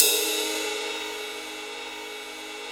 RIDE18.wav